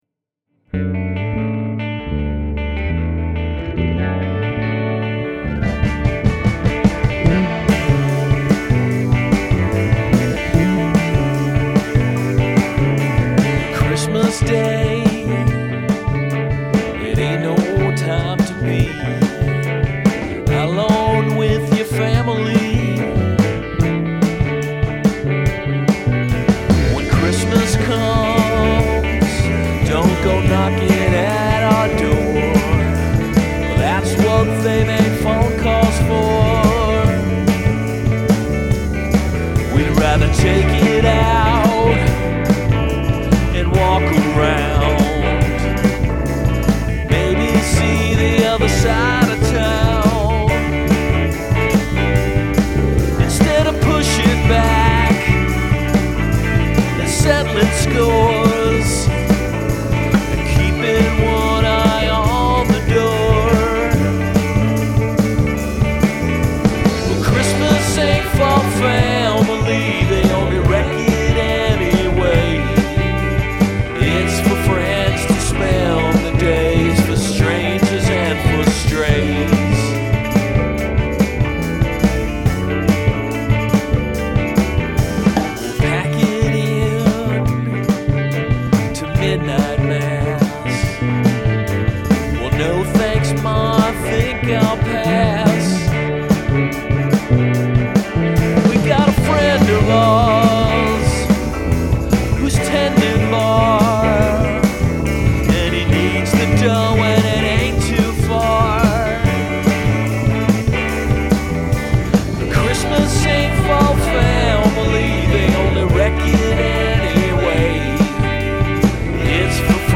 Well, here they are in all their ancient, weirdly mixed and overstuffed glory.